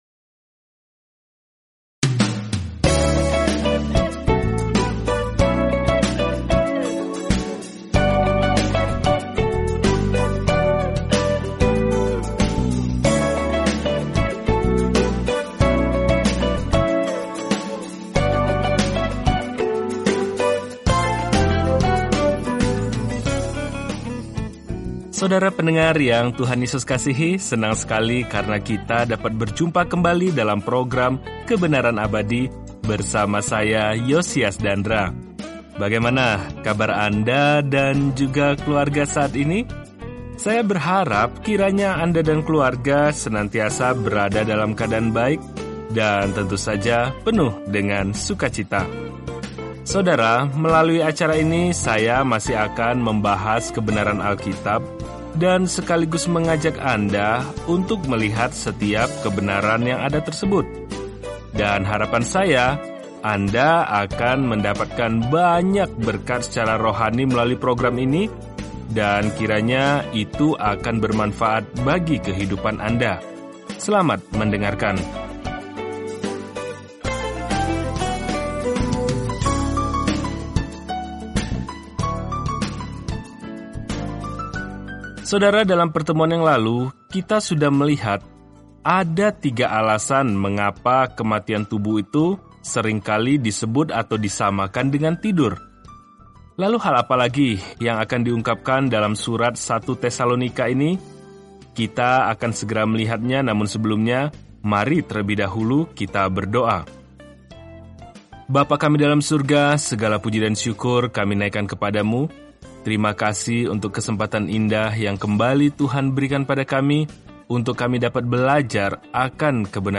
Jelajahi 1 Tesalonika setiap hari sambil mendengarkan pelajaran audio dan membaca ayat-ayat tertentu dari firman Tuhan.